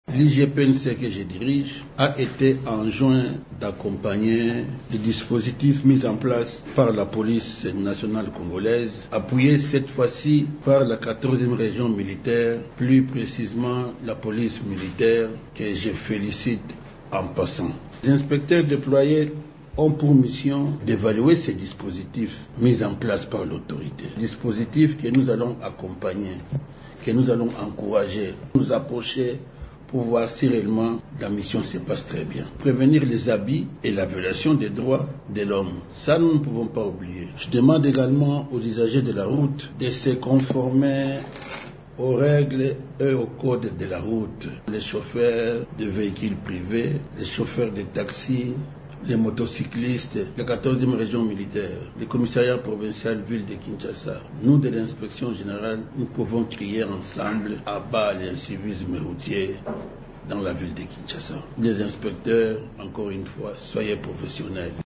Dans un briefing ce mardi, le commissaire divisionnaire principal Mushid Yav a salué et encouragé les efforts de la PCR et de la 14e région militaire de lutter contre les embouteillages à Kinshasa, selon les mesures arrêtées par les autorités du pays.